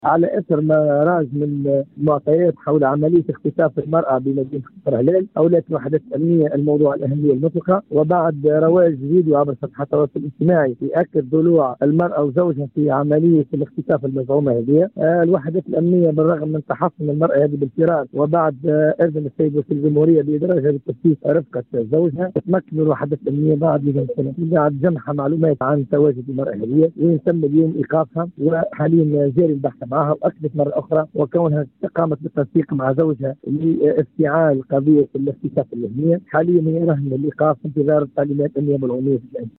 تصريح ل “ام اف ام”